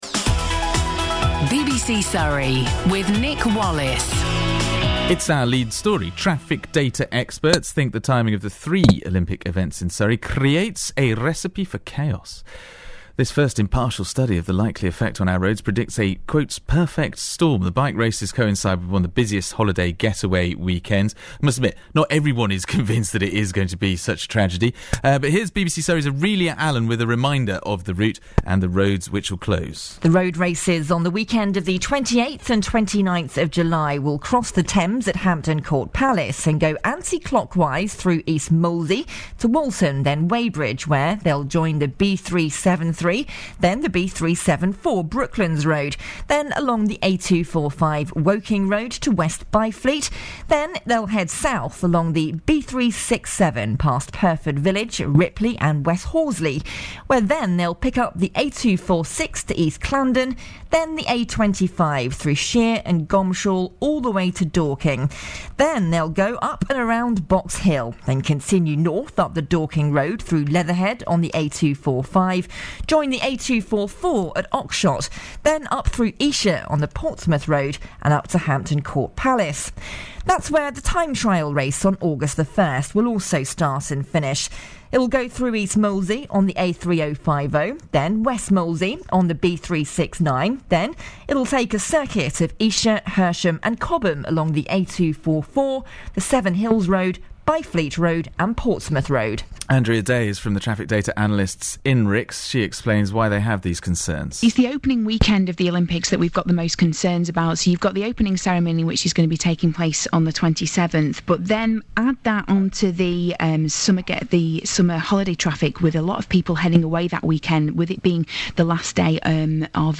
Denise Saliagopoulos interviewed about Olympic traffic
Denise Saliagopoulos, Cabinet Member for the 2012 Games, was interviewed on BBC Surrey about the county council’s work to ensure that there is as little traffic disruption as possible and help residents prepare for the Olympic games.